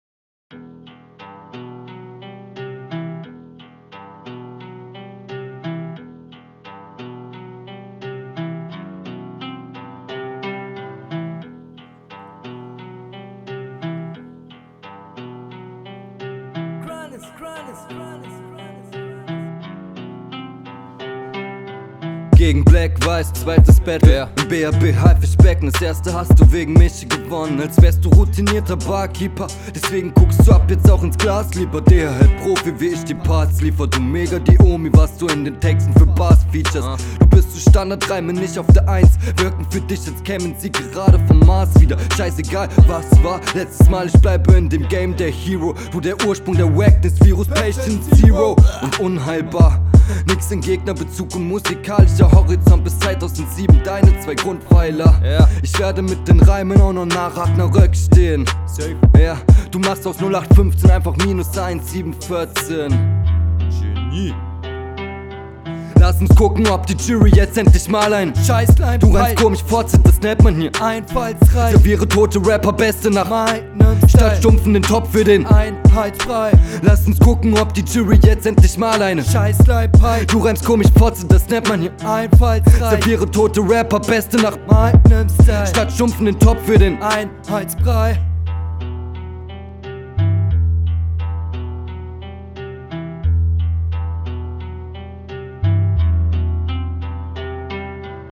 Flow/Punchlines: Selbes Spiel wie in deiner letzten Runde, für mich bist du fast dauerhaft Offbeat, …